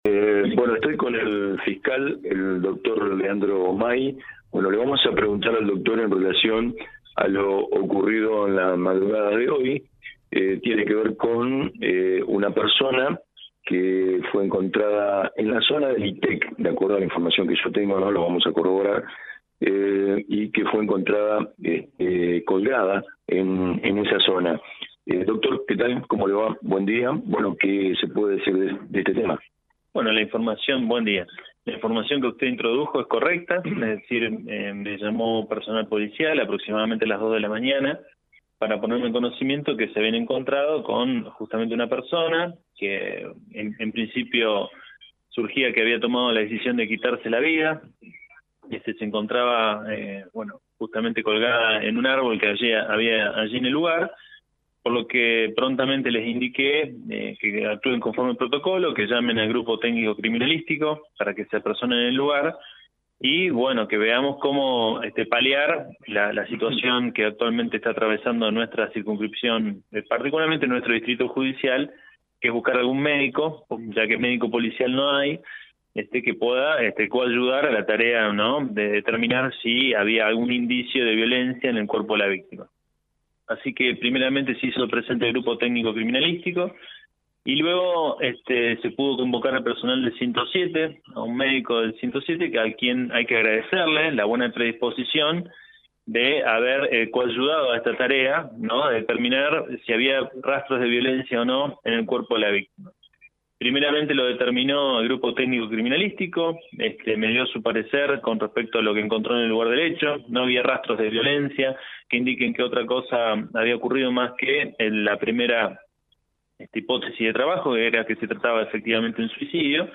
Audio del Fiscal Leandro Mai